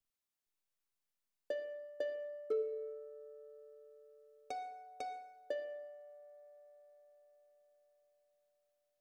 Or che di Febo ascosi (H.511 ; parfois orthographié Hor che di Febo…), est une cantate de chambre du compositeur italien Alessandro Scarlatti, composée pour voix de soprano, deux violons et basse continue.